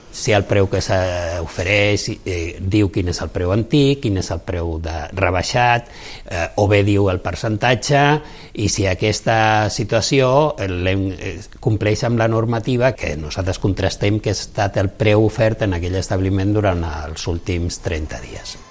Isidor Garcia, director de l’Agència Catalana del Consum, apunta què analitzen.